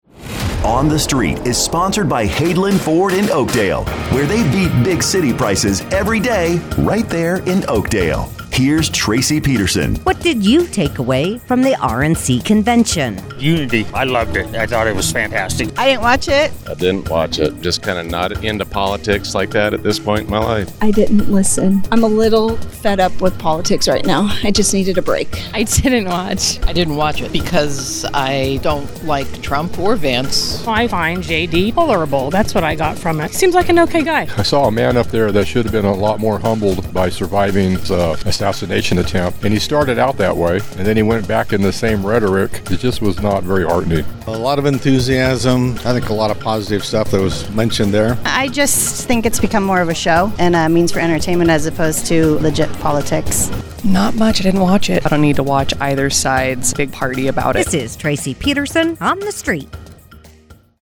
asks Mother Lode residents